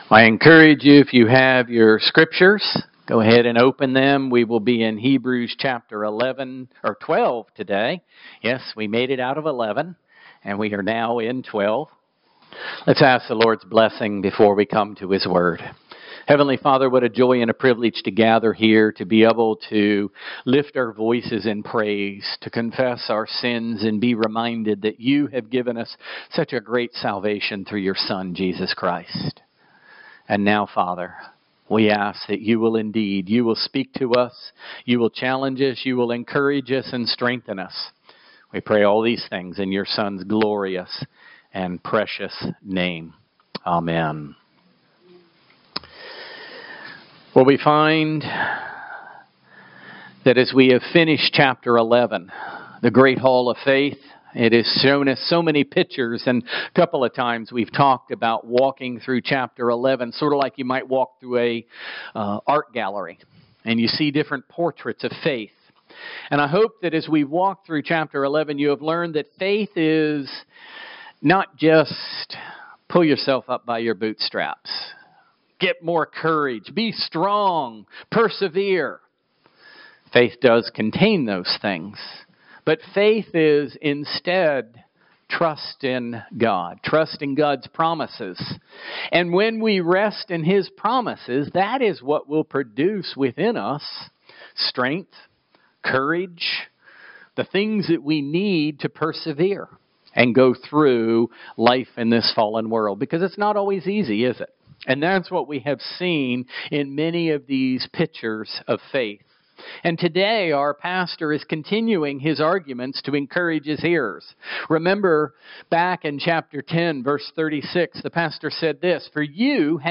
Passage: Hebrews 12:1-3 Service Type: Sunday Morning Worship